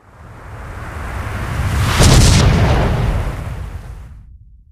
gravi_blowout3.ogg